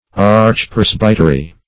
Search Result for " archpresbytery" : The Collaborative International Dictionary of English v.0.48: Archpresbytery \Arch`pres"by*ter*y\, n. [Pref. arch- + presbytery.]